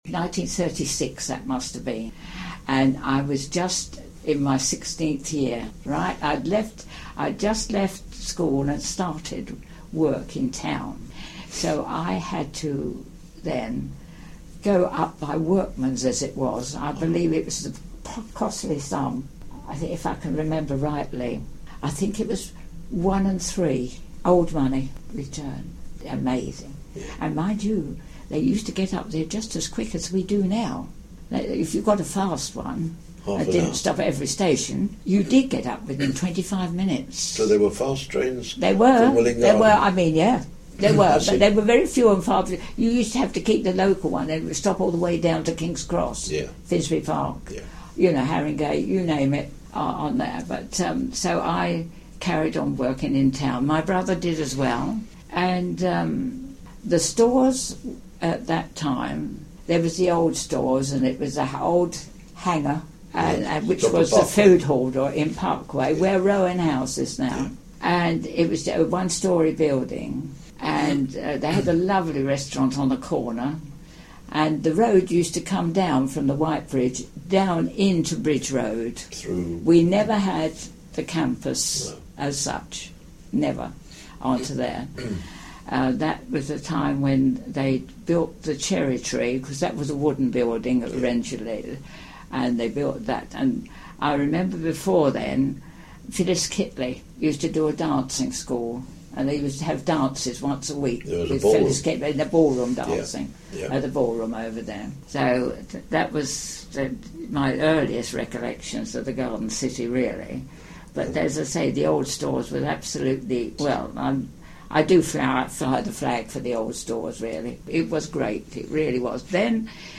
Memories - early residents contributed their recollections of the town which were digitally recorded as oral histories by a team of volunteers.